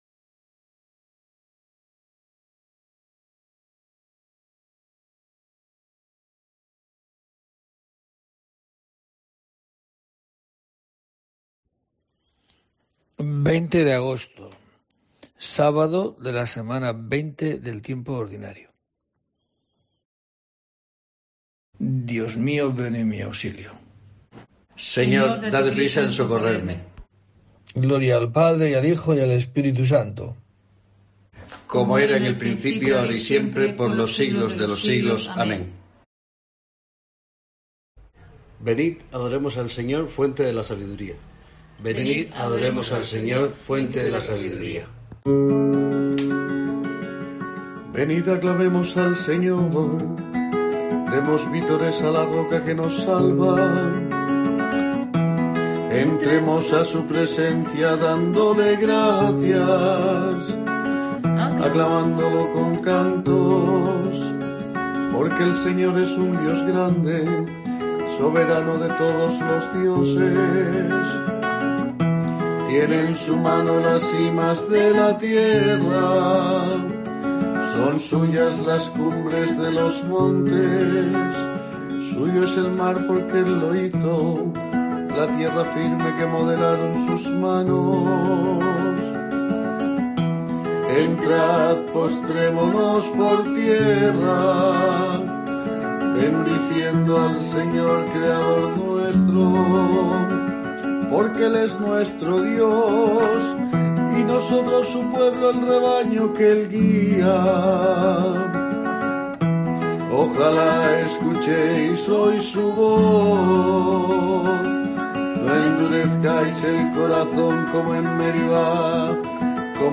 20 de agosto: COPE te trae el rezo diario de los Laudes para acompañarte